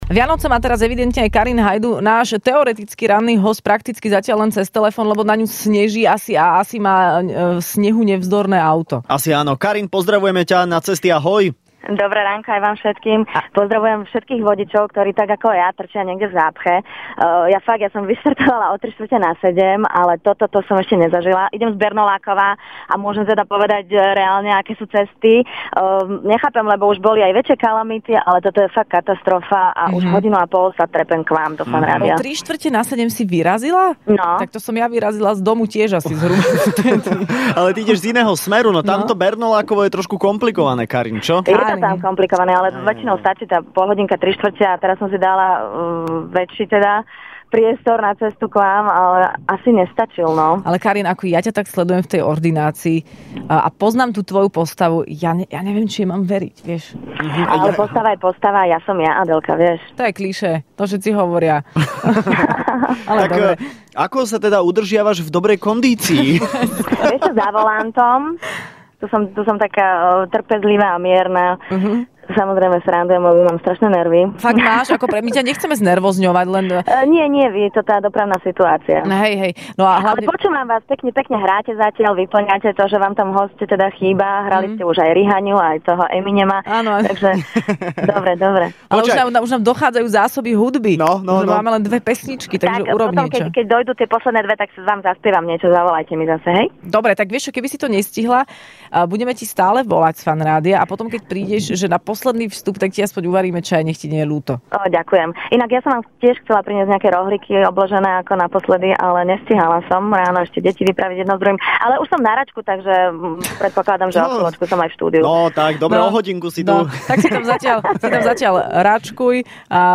V Rannej šou bola dnes hosťom herečka Karin Haydu.